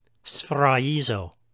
Pronunciation Note: When a single Gamma (γ) is followed by the vowels Epsilon (ε) or Iota (ι), or by the digraph Epsilon-Iota (ει) as in the word σφραγίζω, the Gamma is pronounced like the y in yet.